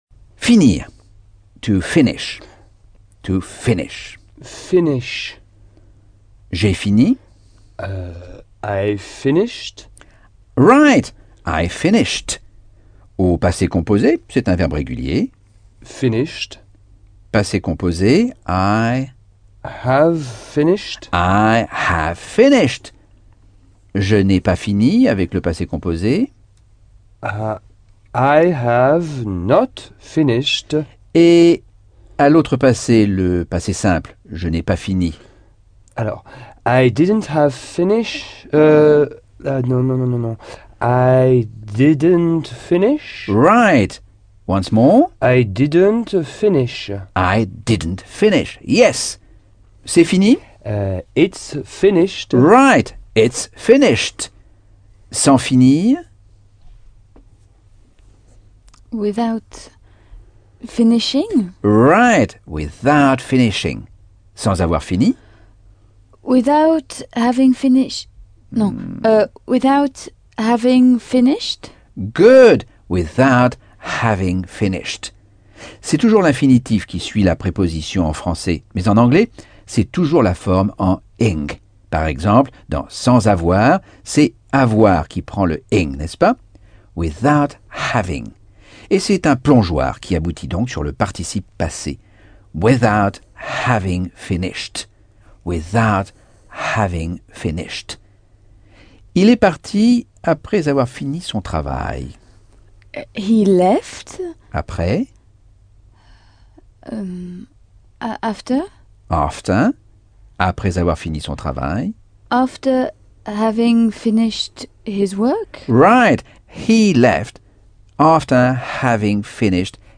Leçon 8 - Cours audio Anglais par Michel Thomas - Chapitre 10